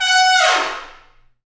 Index of /90_sSampleCDs/Roland L-CDX-03 Disk 2/BRS_Tpts FX menu/BRS_Tps Falls